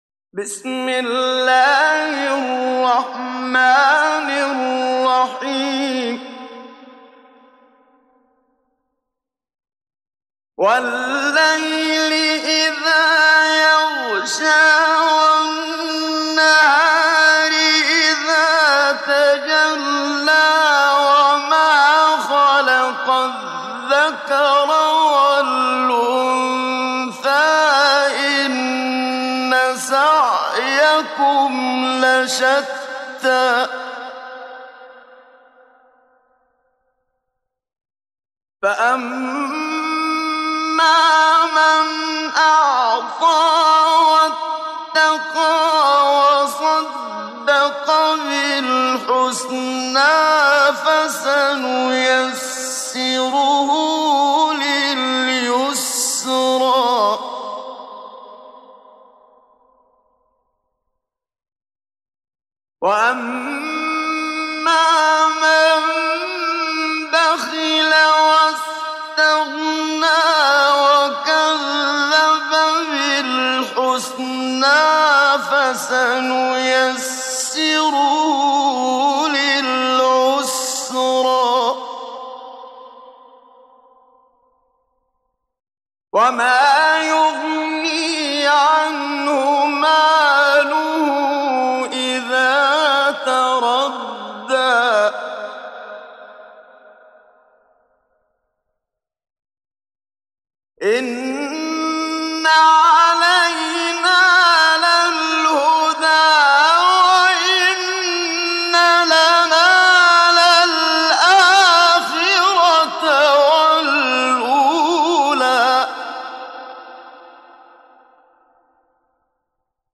ডাউনলোড সূরা আল-লাইল Muhammad Siddiq Minshawi Mujawwad